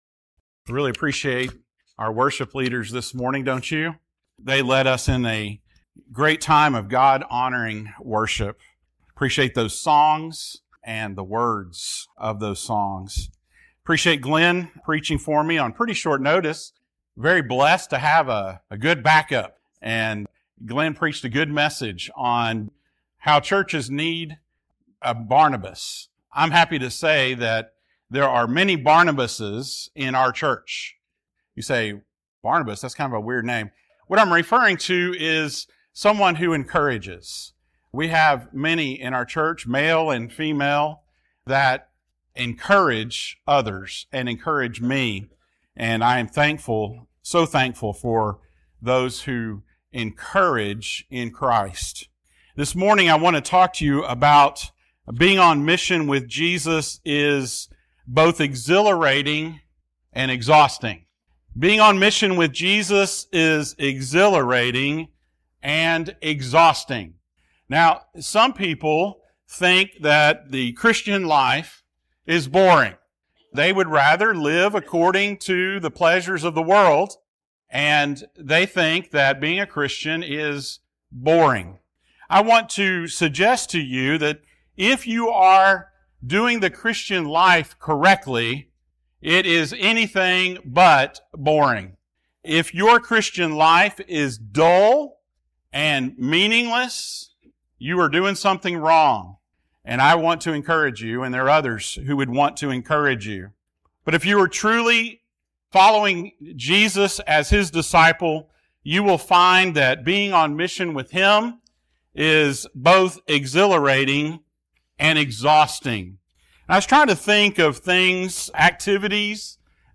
Passage: Mark 6:12-13, 30-34 Service Type: Sunday Morning